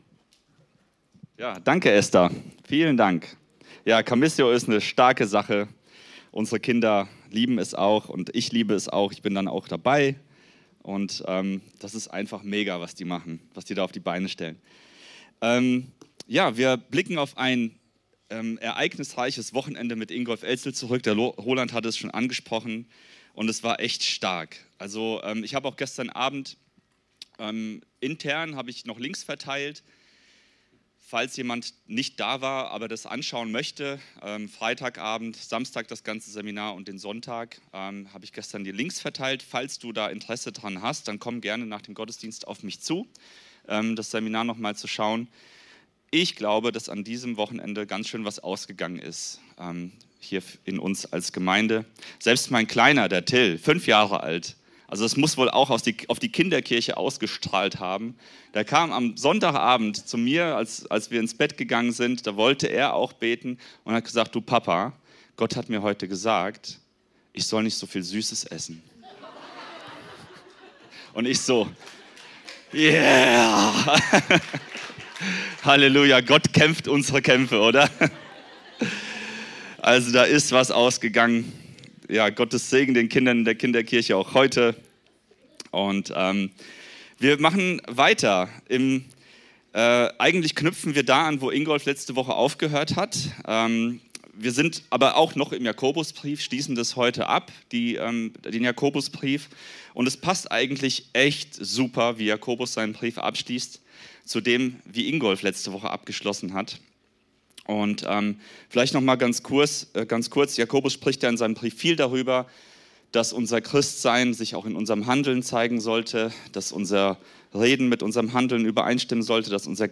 Predigten aus der freien Christengemeinde Die Brücke in Bad Kreuznach.